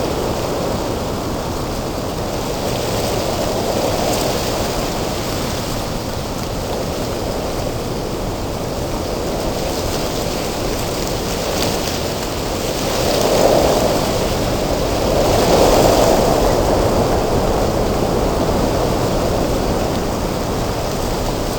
tree_mono_01.ogg